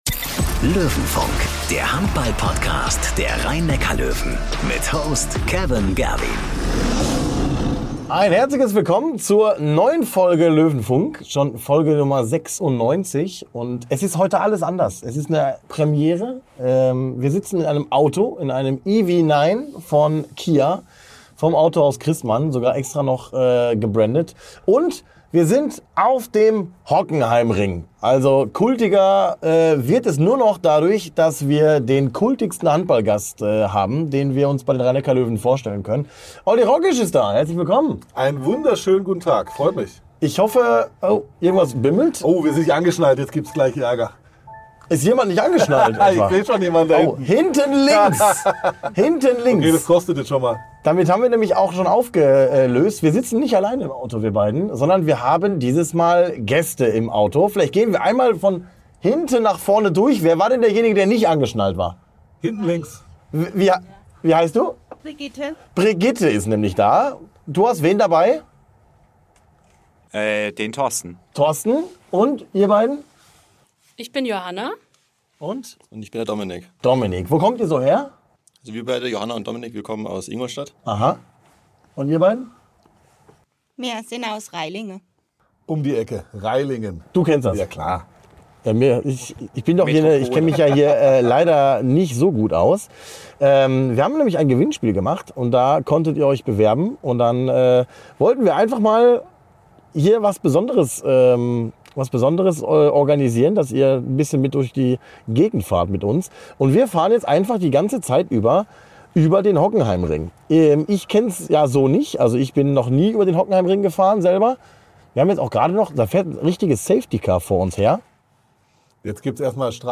Nicht inhaltlich, sondern im reinen Wortsinn, denn Dank Löwen-Partner Autohaus Christmann kommt der Podcast dieses Mal vom Hockenheimring in die Ohren der Handball-Fans. Mit dabei sind zudem vier Löwenfans, allesamt Mitglieder im Löwenrudel, die zu den glücklichen Gewinnern der Auslosung unter allen teilnehmenden Mitgliedern gehört haben und die Chance nutzen, exklusiver Teil einer Löwenfunk-Aufnahme zu sein. Inhaltlich geht es u.a. um den Saisonstart der Löwen sowie um Olis neue Aufgaben bei den Löwen und beim DHB.